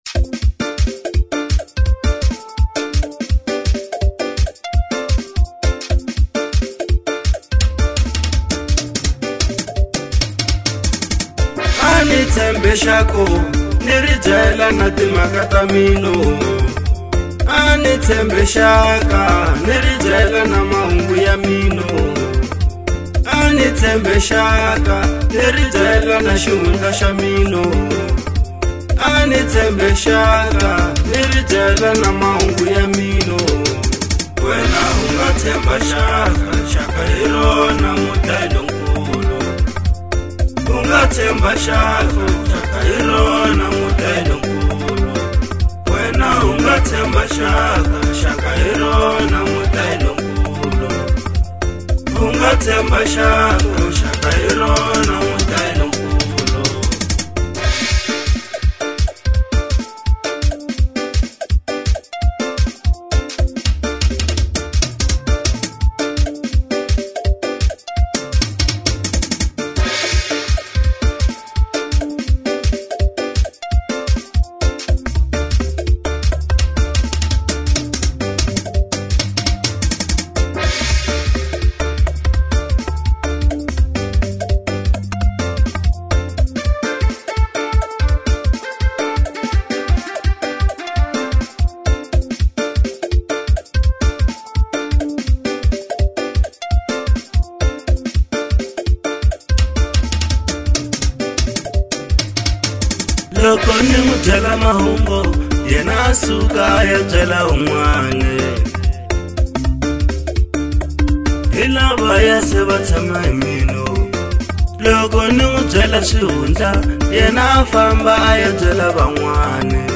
04:07 Genre : Xitsonga Size